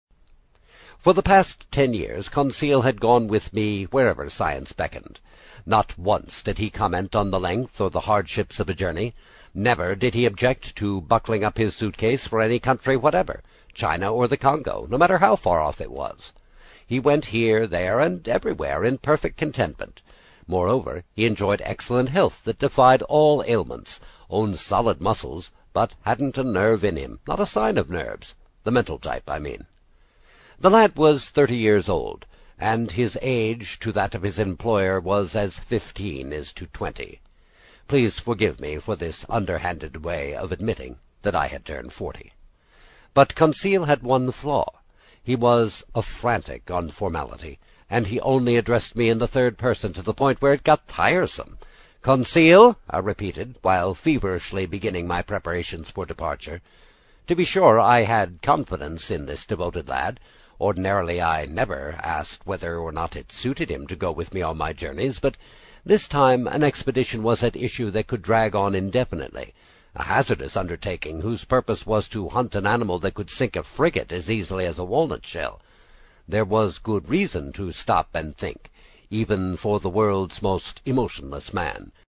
在线英语听力室英语听书《海底两万里》第25期 第3章 随您先生的便(3)的听力文件下载,《海底两万里》中英双语有声读物附MP3下载